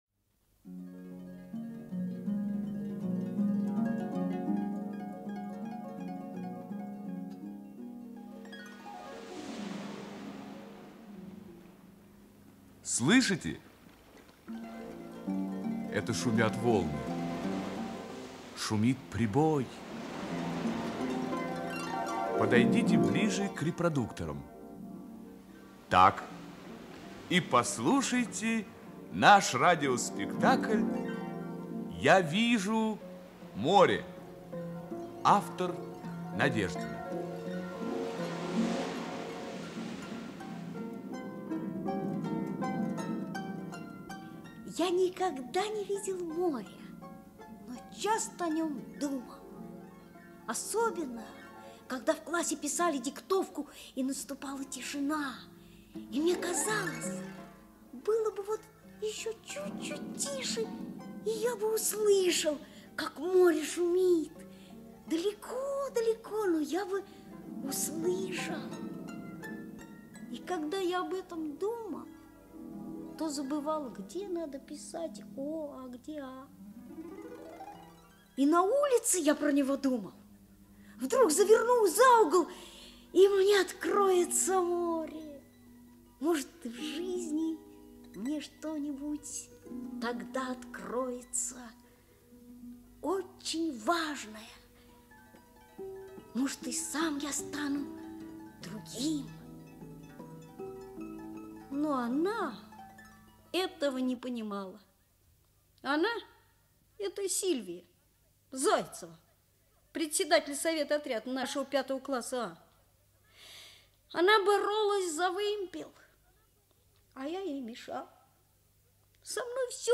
Радиопостановка
n.nadejdina.-ya-viju-more.-radiopostanovka.mp3